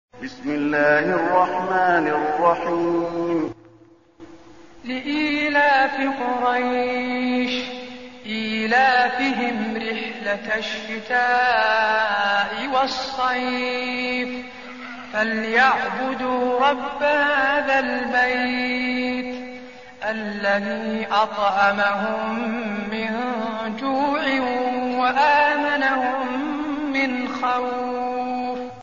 المكان: المسجد النبوي قريش